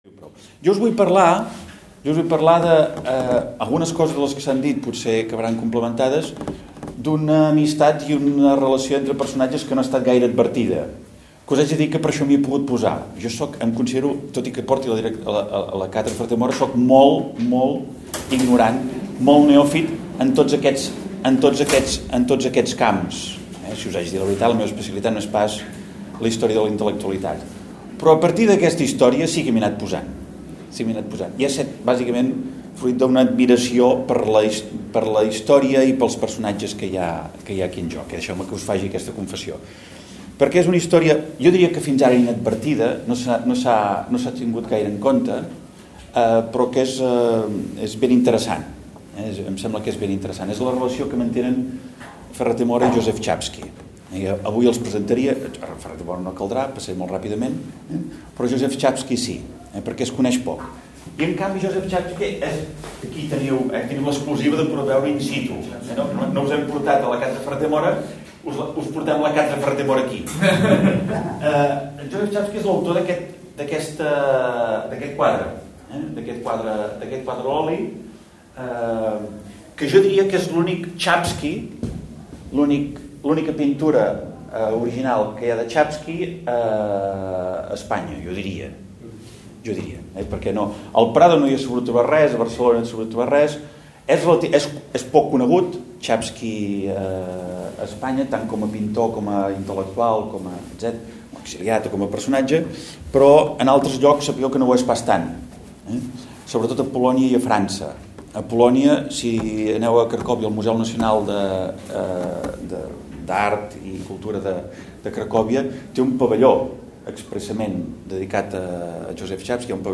Conferència